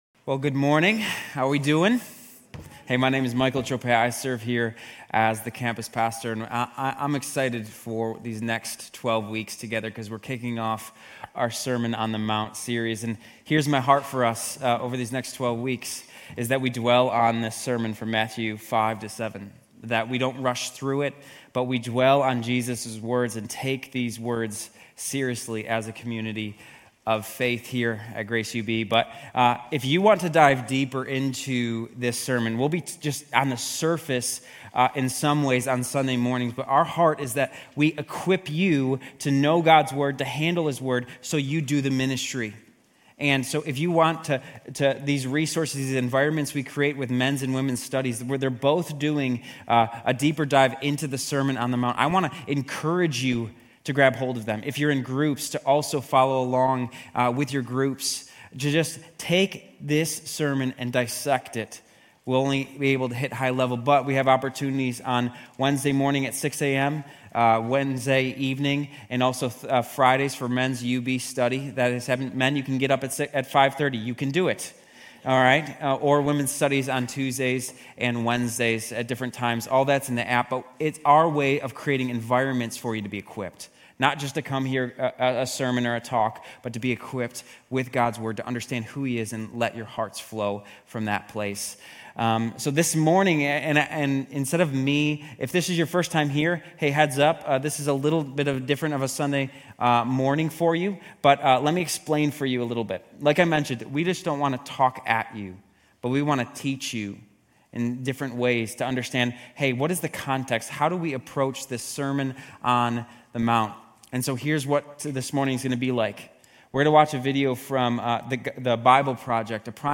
Grace Community Church University Blvd Campus Sermons Jan 19 - University Blvd Campus Jan 05 2025 | 00:32:23 1x 00:00 / 00:32:23 Subscribe Share RSS Feed Share Link Embed